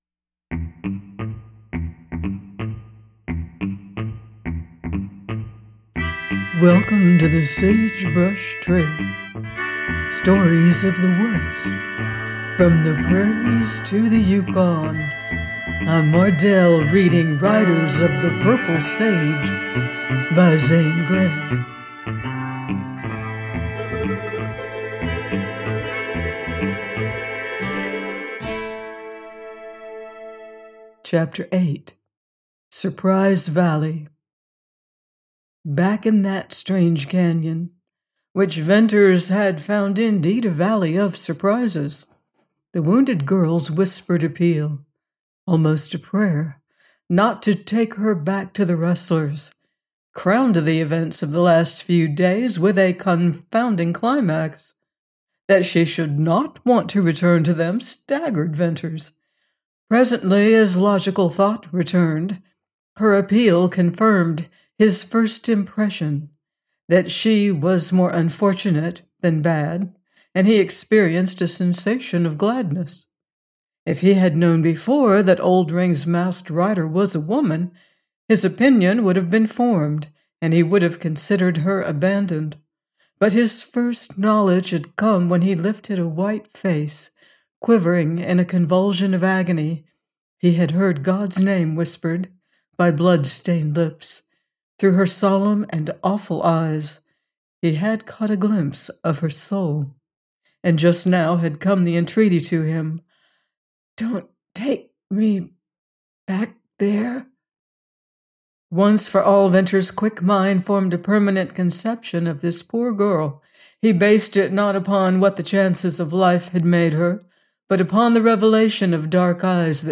Riders Of The Purple Sage – Ch. 8: by Zane Grey - audiobook